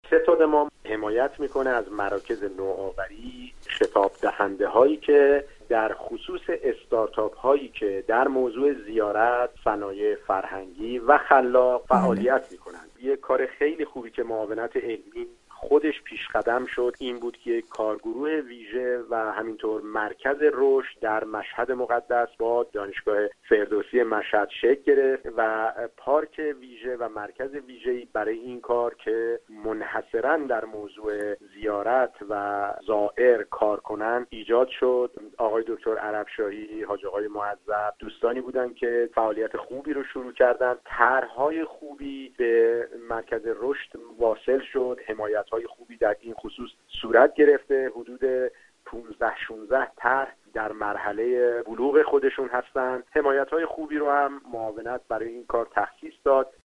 پرویز کرمی، دبیر ستاد فرهنگ‌سازی اقتصاد دانش‌بنیان و توسعه صنایع نرم و خلاق معاونت علمی و فناوری رییس جمهوری در گفتگوی ویژه خبری رادیو زیارت با بیان اینکه ایده ها و خلاقیت دانشجو برای عملی شدن نیاز به حمایتهای مادی و معنوی دارندگفت: